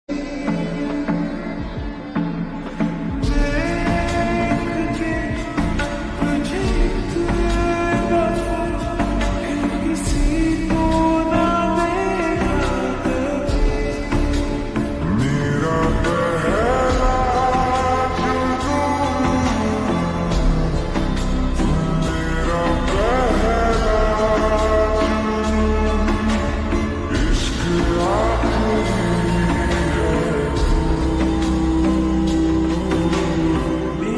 ( Slowed + Reverb)